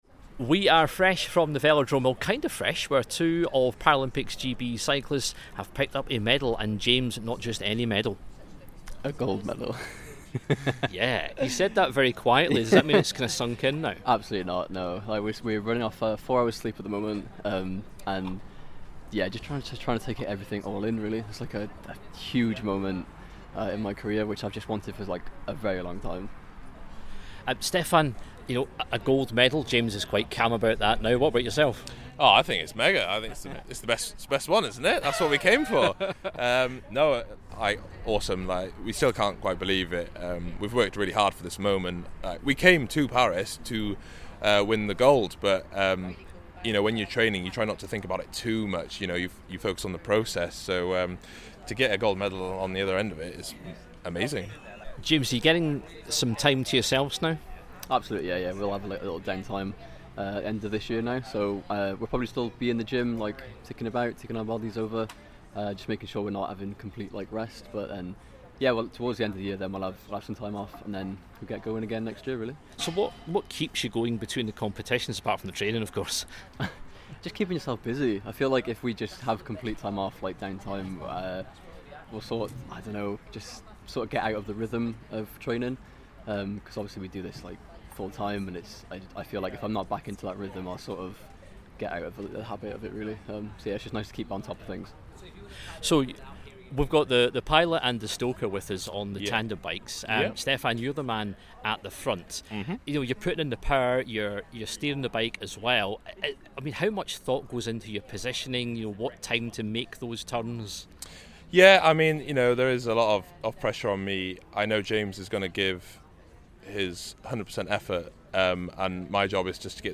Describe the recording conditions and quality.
And more form the terrace at Paralympics GB House